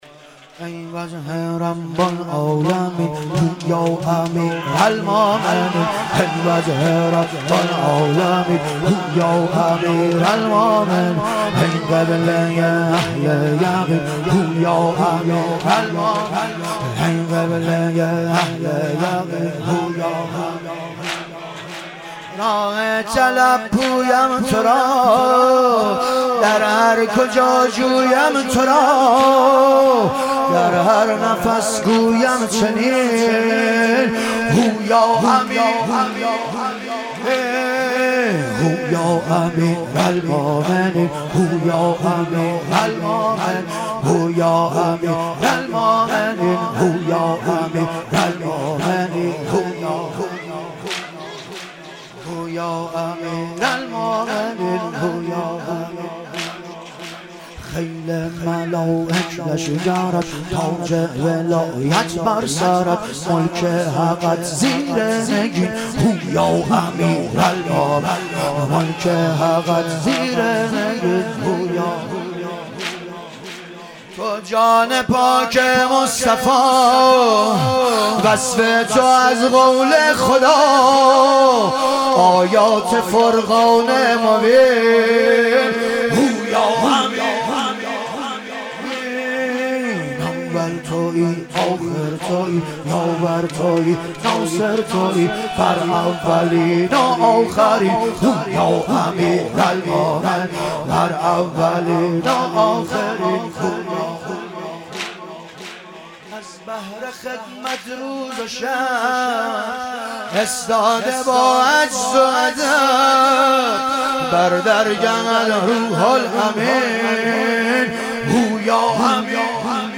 ایام فاطمیه اول - واحد